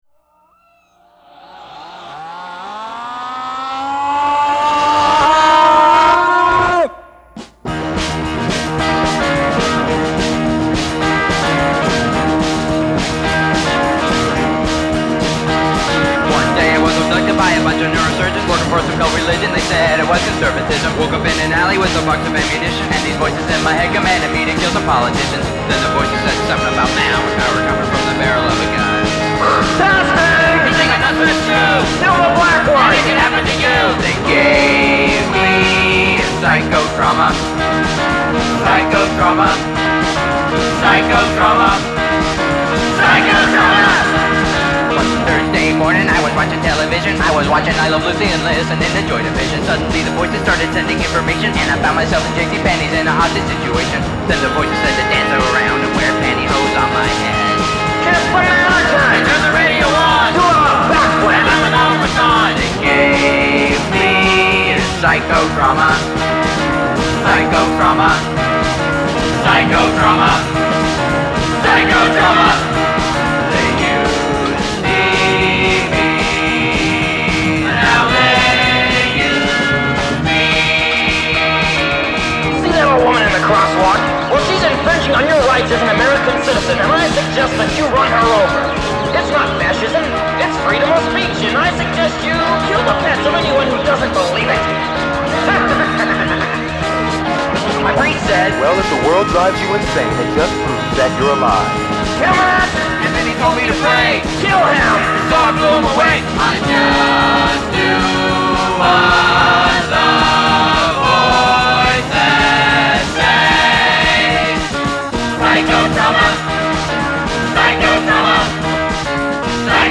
Just your average hopped-up song about mind control.